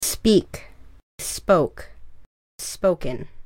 Рядом я подготовила произношение и перевод.